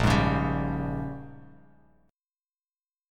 A#m13 chord